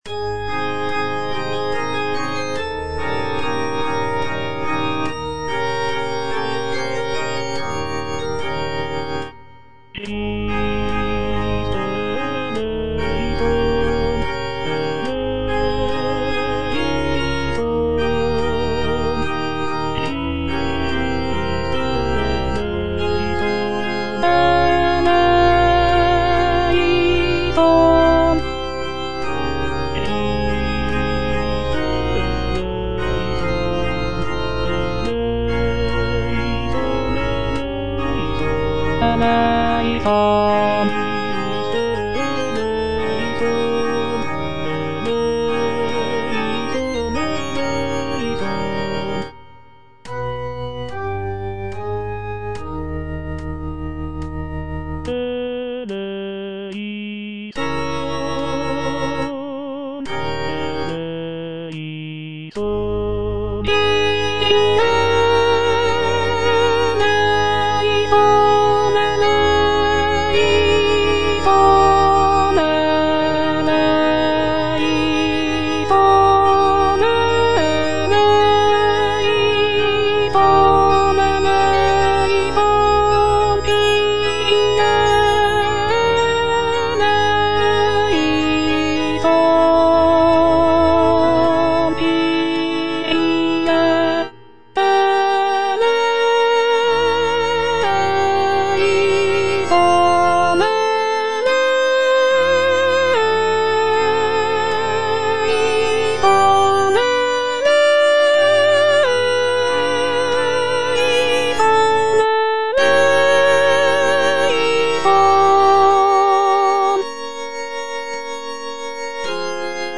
C.M. VON WEBER - MISSA SANCTA NO.1 Christe eleison - Alto (Voice with metronome) Ads stop: auto-stop